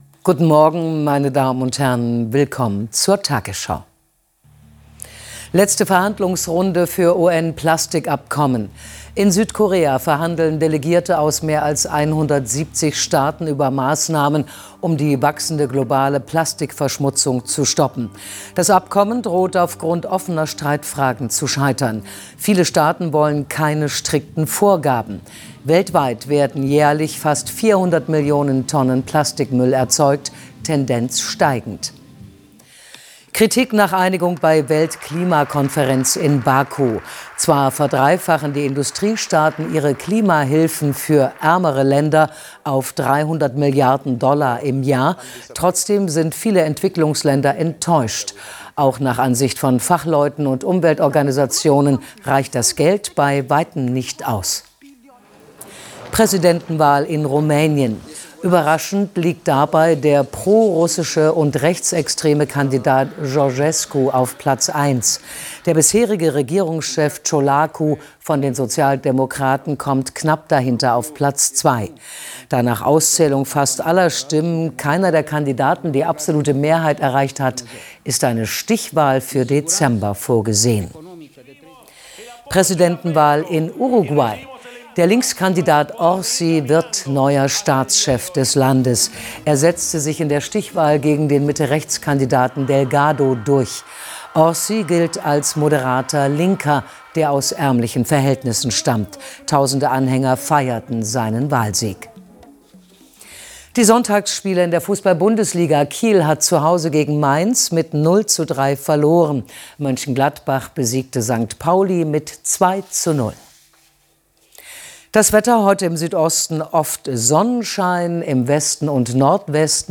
Der tagesschau-Nachrichtenüberblick in 100 Sekunden als Audio-Podcast